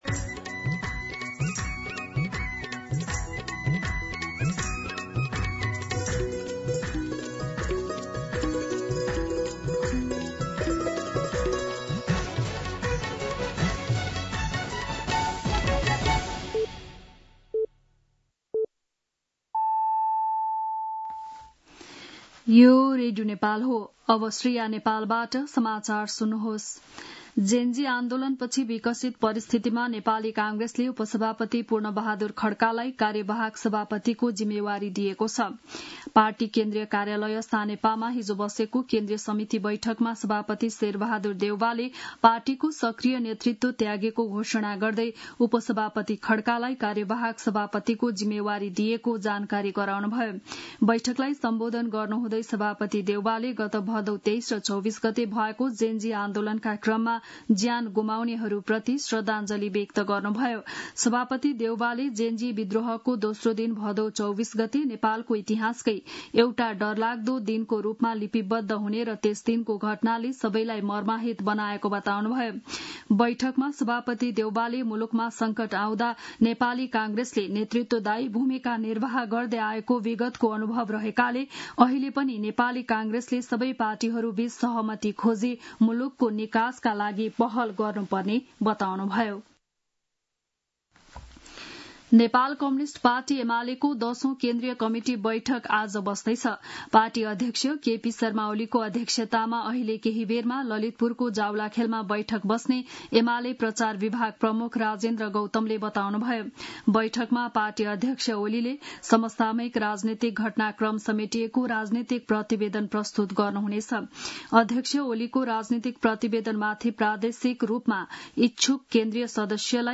बिहान ११ बजेको नेपाली समाचार : २९ असोज , २०८२